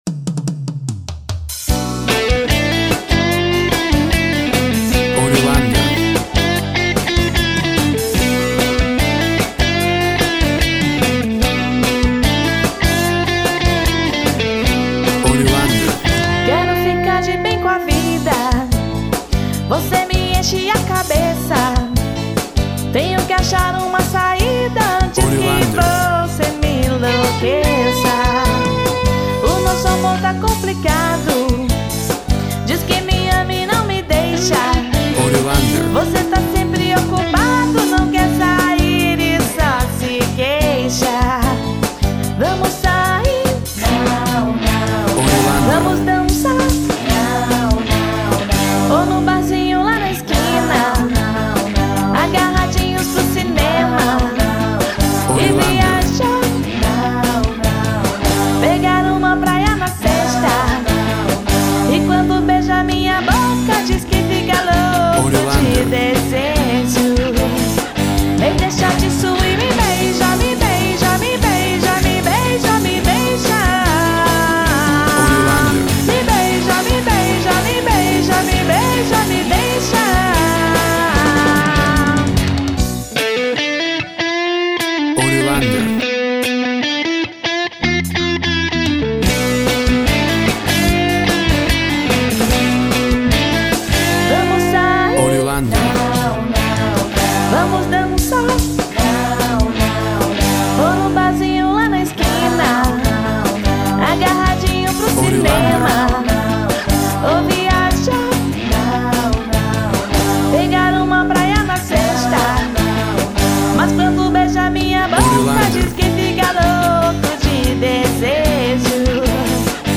Pop Rock
Tempo (BPM) 150